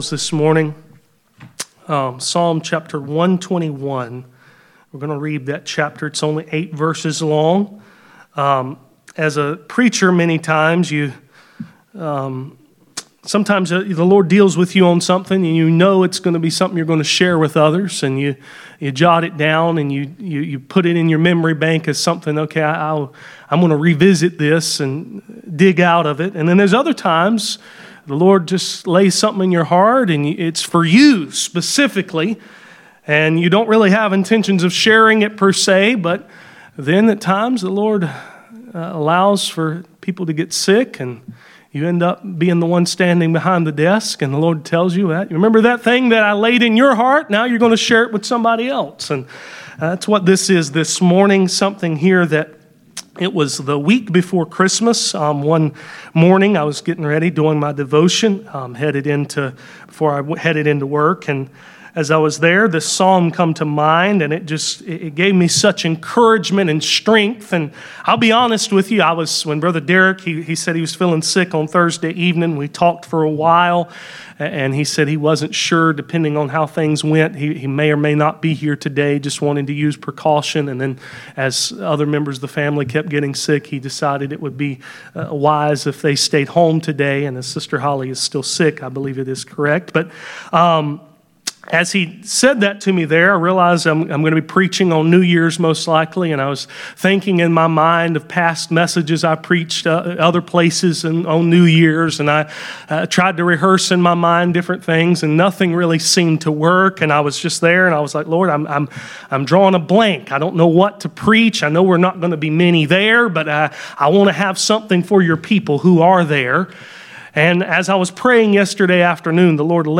Passage: Psalm 121:1-8 Service Type: Sunday Morning %todo_render% « Conceived by the Holy Ghost